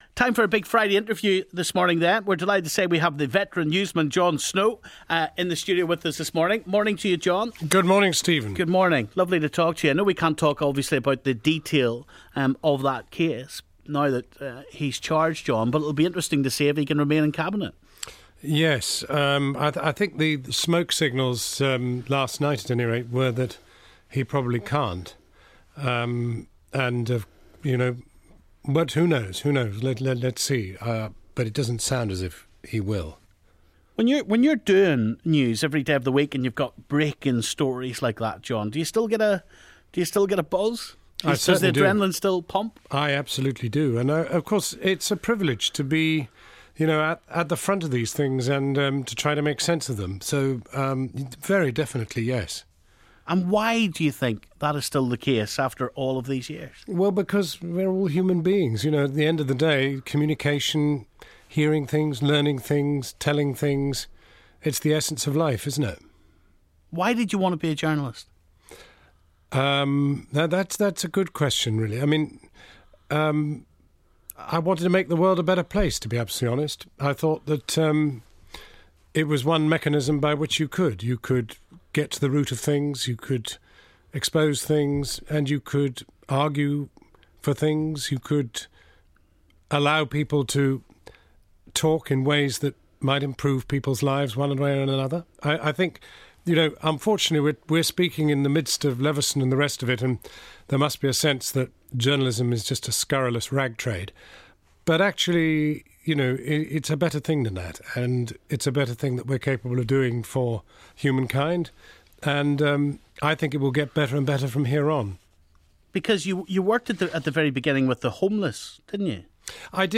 In this week's Big Friday Interview Veteran Broadcaster Jon Snow talks about his career